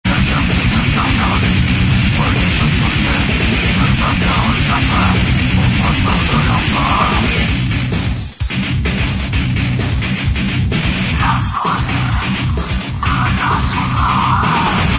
death métal